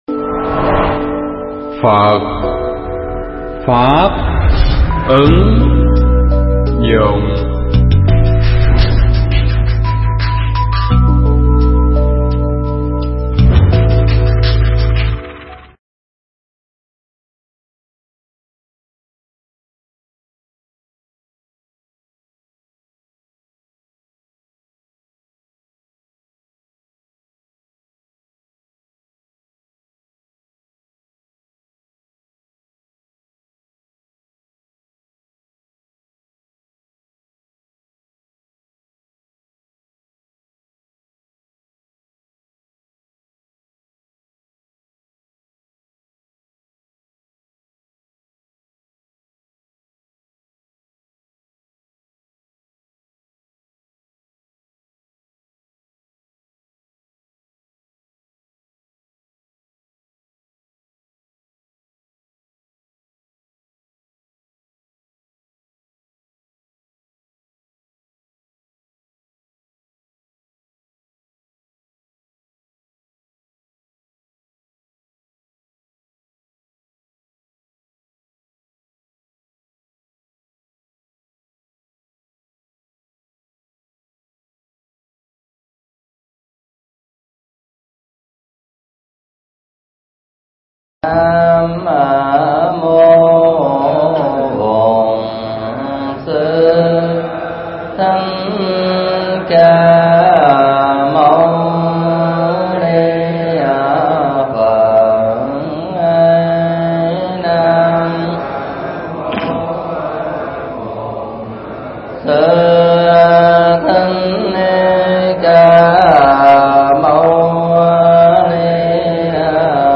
Nghe mp3 pháp thoại Kinh Kim Cang 2 thuộc tuyển tập những bài pháp thoại về Phật Học
thuyết giảng tại Tu Viện Tường Vân